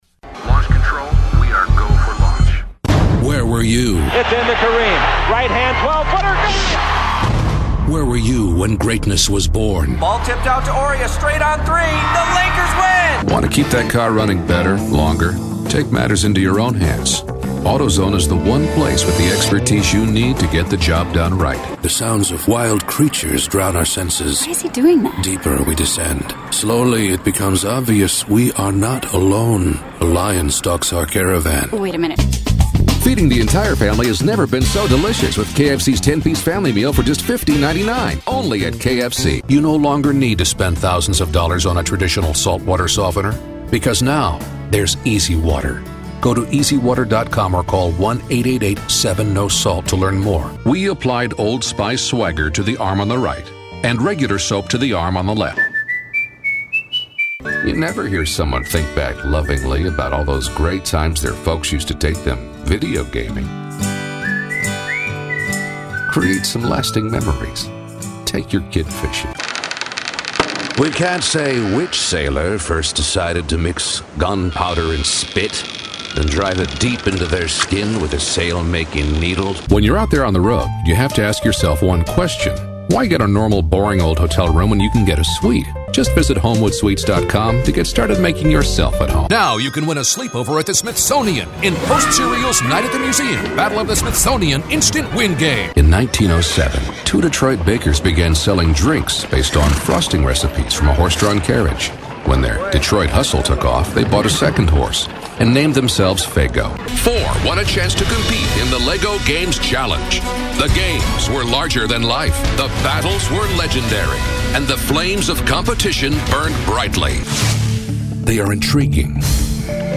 Iconic, Dramatic, Impeccable.
Commercial